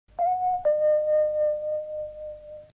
Indicatiu i toc de l'hora, entrada i sortida butlletí de cada 15 minuts.